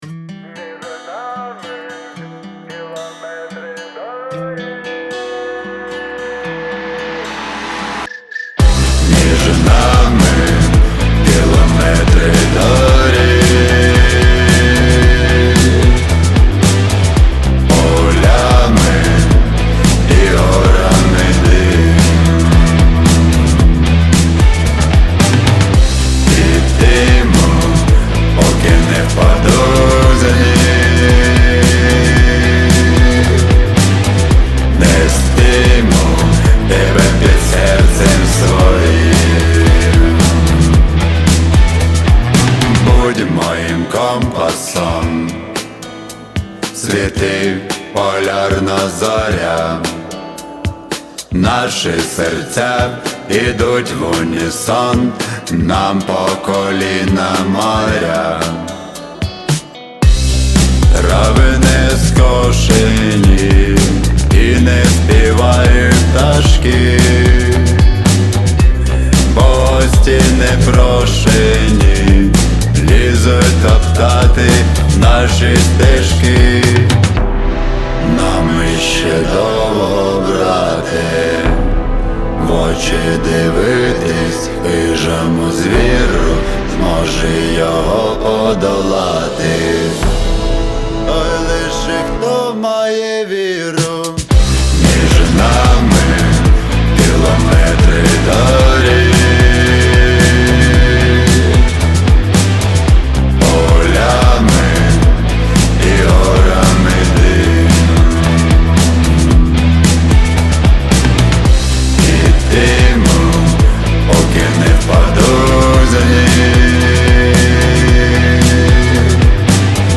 • Жанр: Pop, Folk